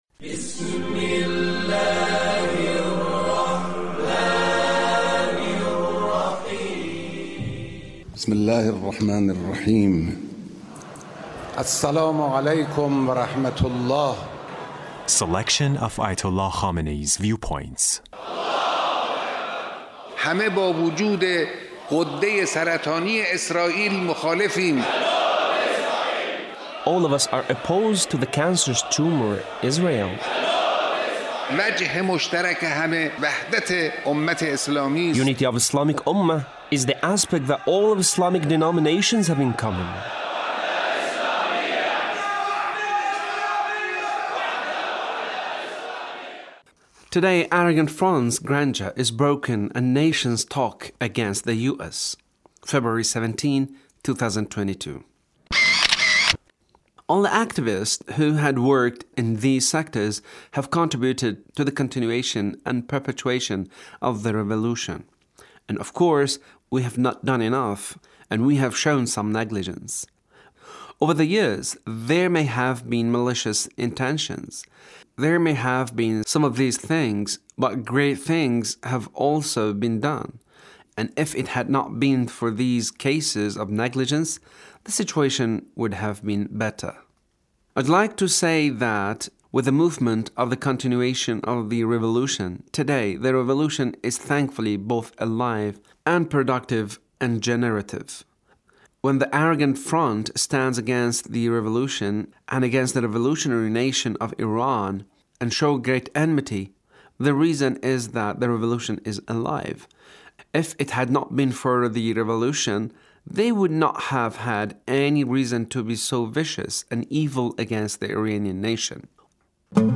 The Leader's speech